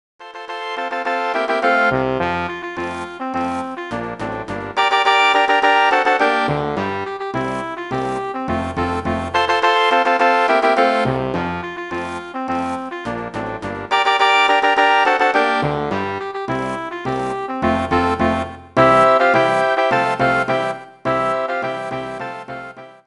Gattung: Marsch Besetzung: Blasorchester PDF